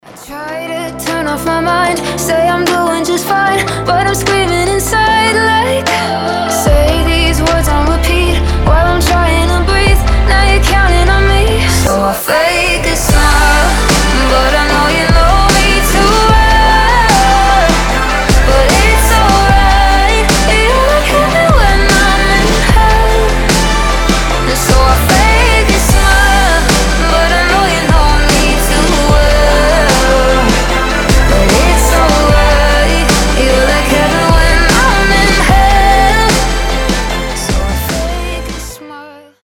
красивый женский голос
future bass